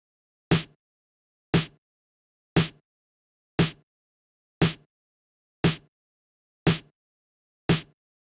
11 Snare.wav